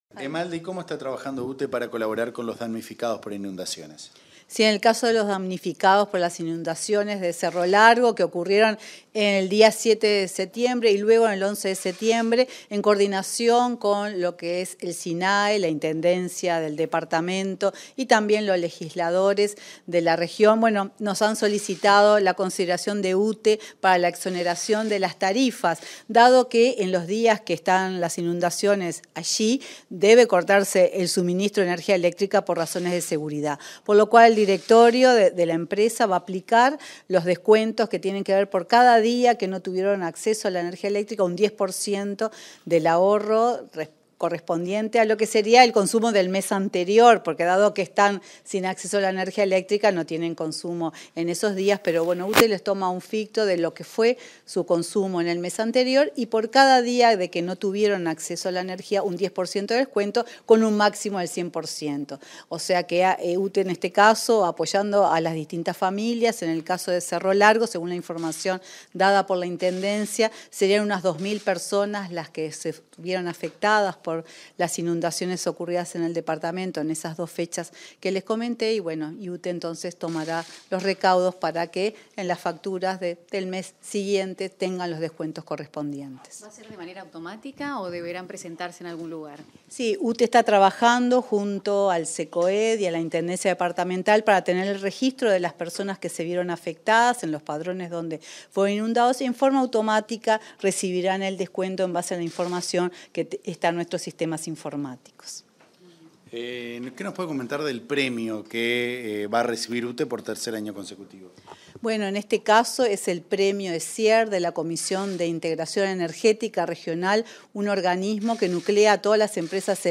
Declaraciones de la presidenta de UTE, Silvia Emaldi, a la prensa